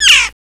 CHIRP.WAV